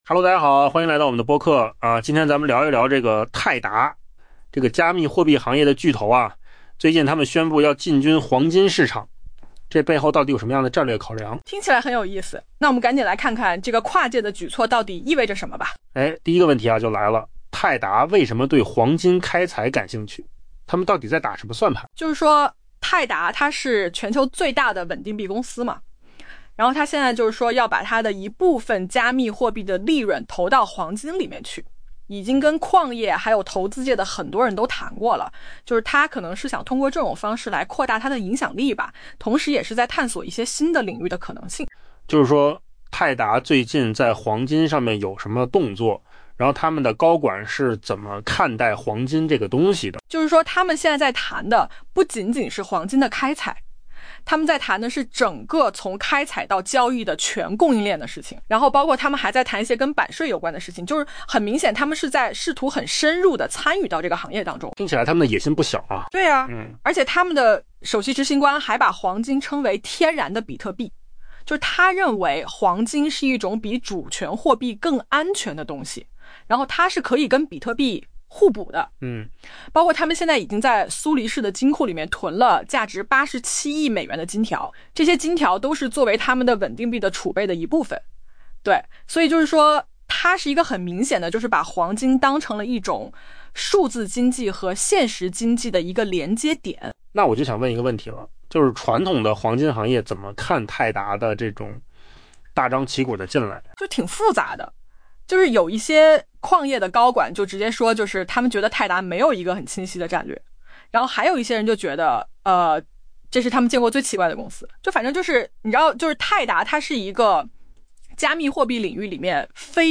AI 播客：换个方式听新闻 下载 mp3 音频由扣子空间生成 全球最大的稳定币公司泰达 （Tether） 近期与矿业和投资团体进行了谈判， 计划投资黄金开采，将其庞大的加密货币利润投入黄金市场。